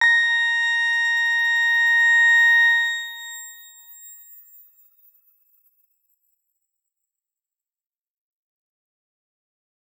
X_Grain-A#5-mf.wav